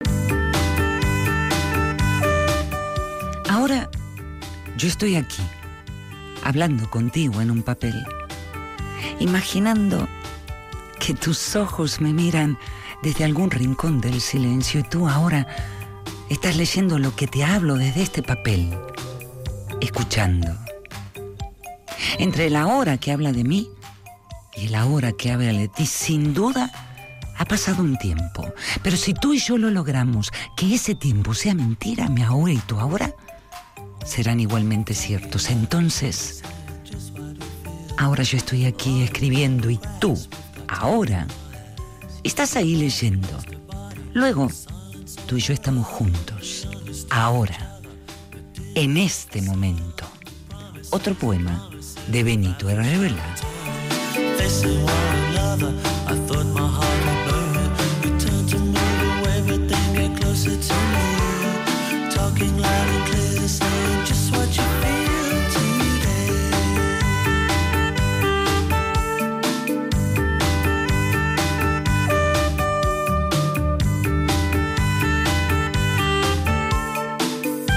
Rapsodeando